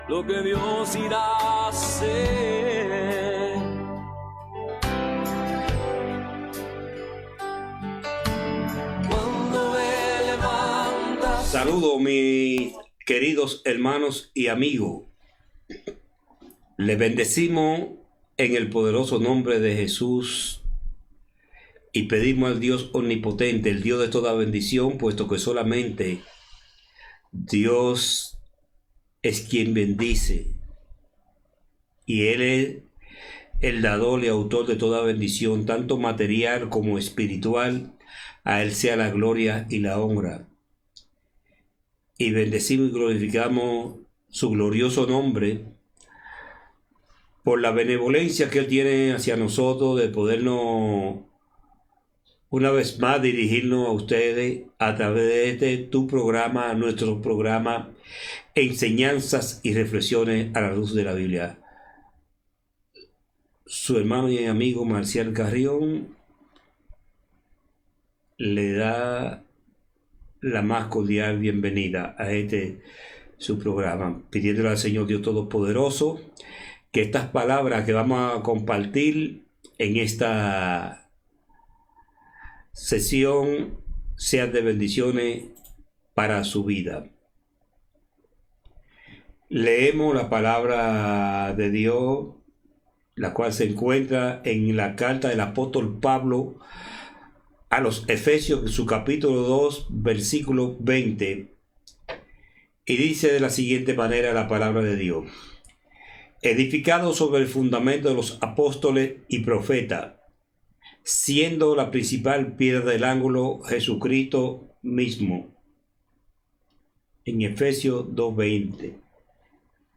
Benvinguda, prec, lectura de la carta de Pau als efesis, oració
Religió